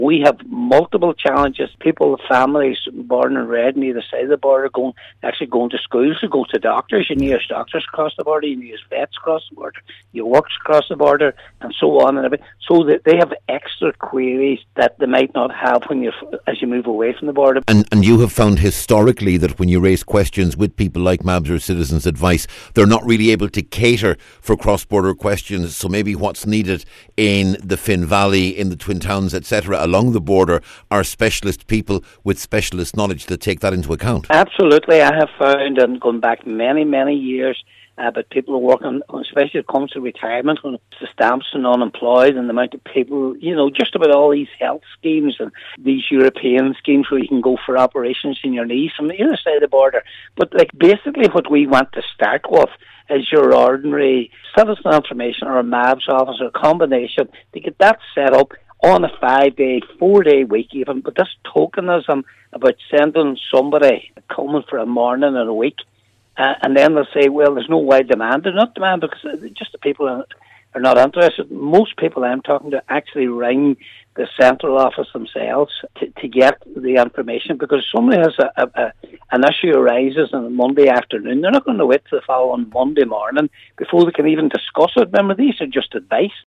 Cllr Mc Gowan told a municipal district meeting this week that occasionally, someone is sent to the area for one day, but something more regular is needed……….